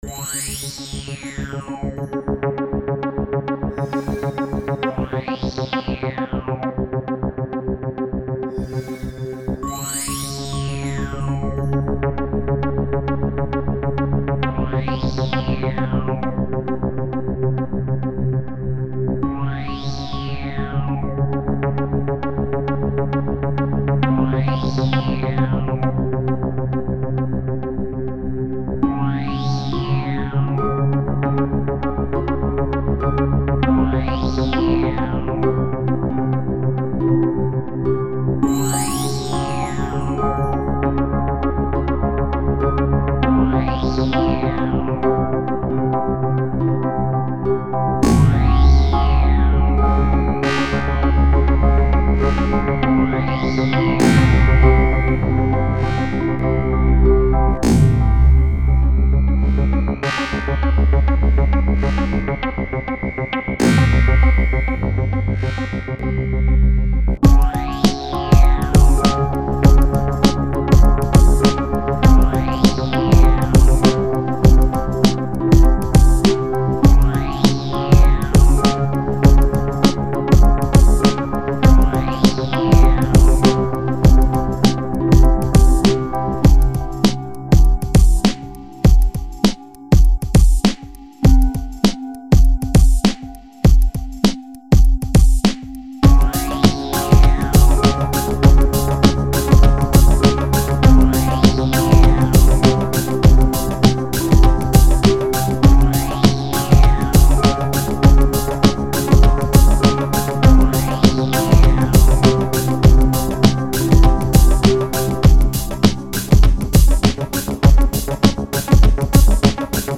chimes og vinsćl fruity loops preset handa krökkunum